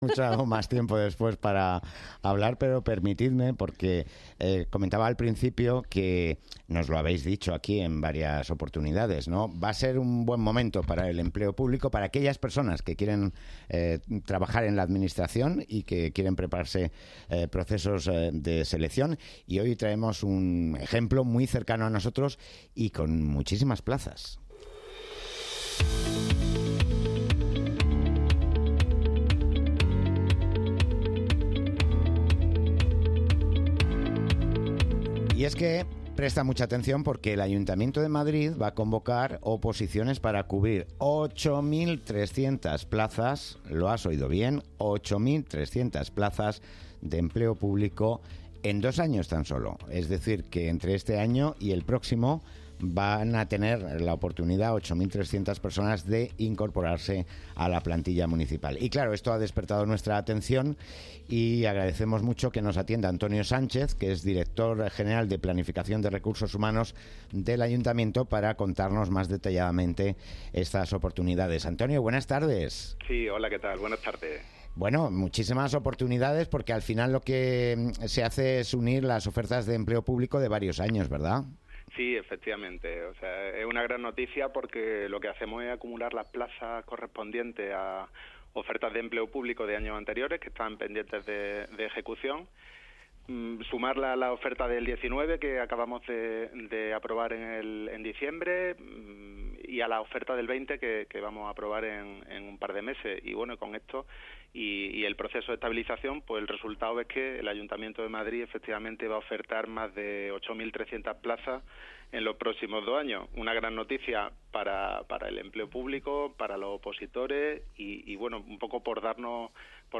Nueva ventana:Entrevista a don Antonio Sánchez Díaz. Director General de Planificación de Recursos Humanos.